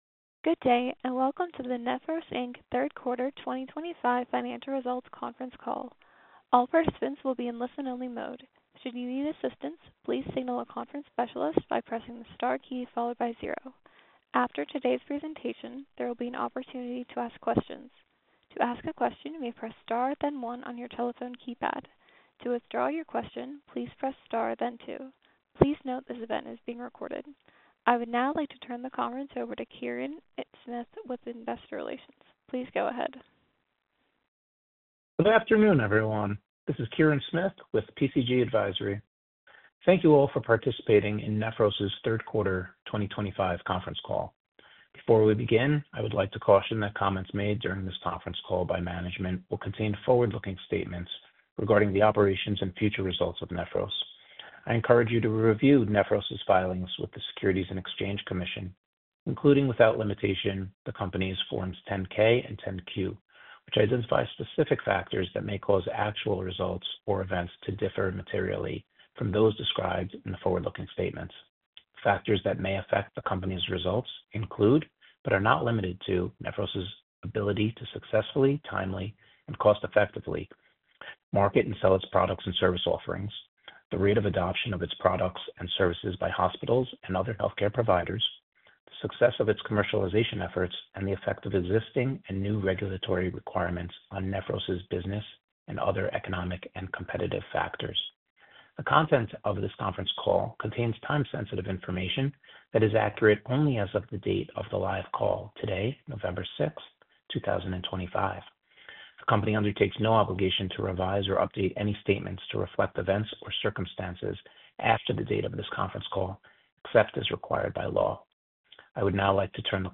Listen to the replay of the Q3 2025 conference call